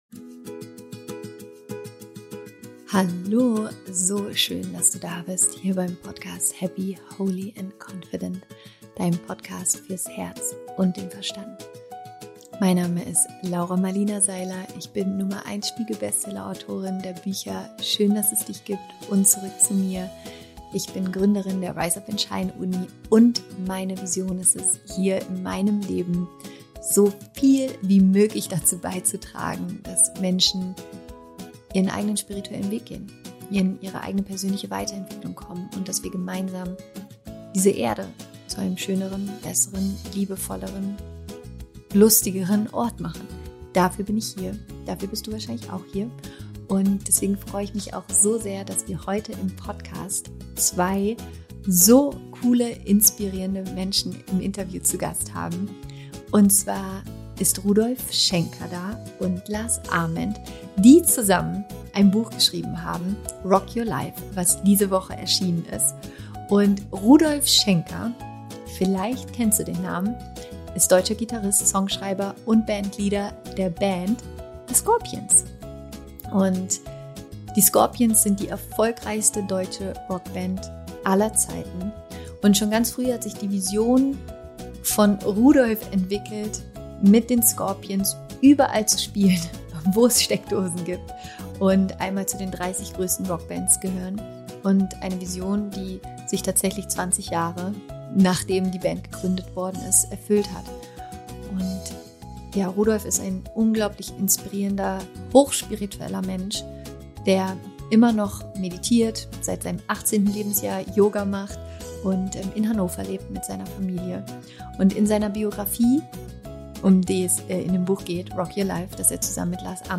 Wie du trotzdem weitermachst, auch wenn niemand an dich glaubt - Interview Special mit Rudolf Schenker & Lars Amend
Darüber spreche ich heute mit Rudolf Schenker und Lars Amend.